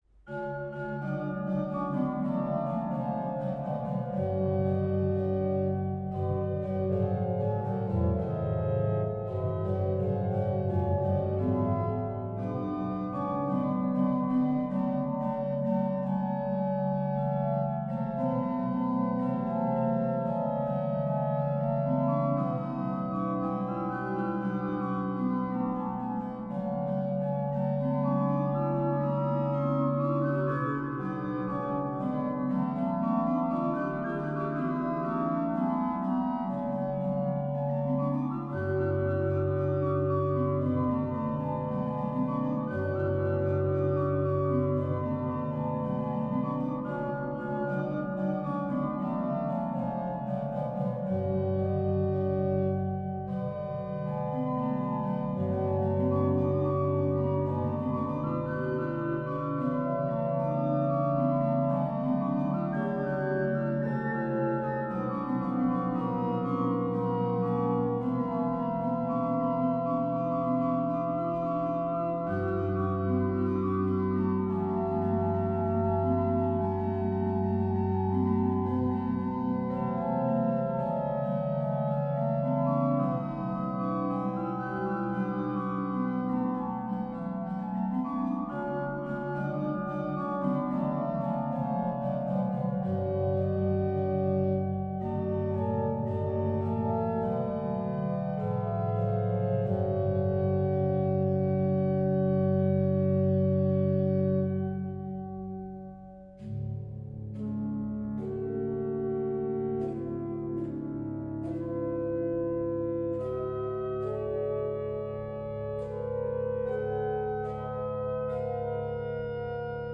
organ Duration